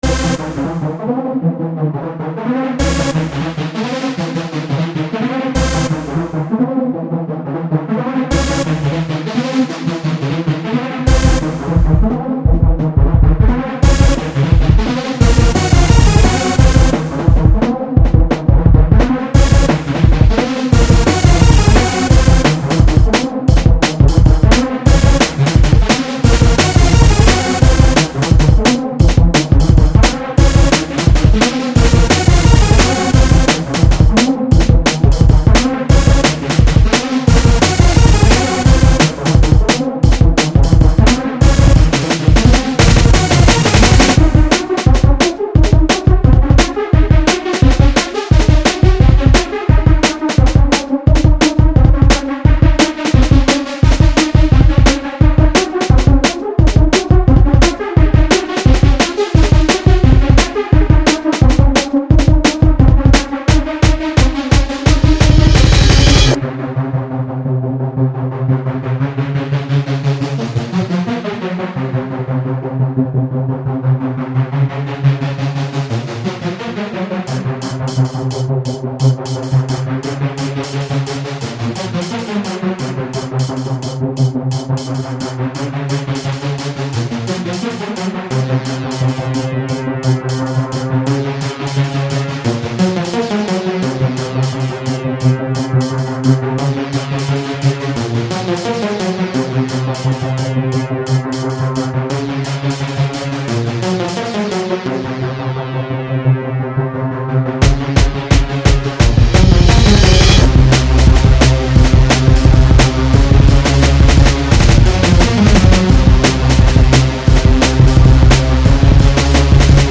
2008-2009 Электронная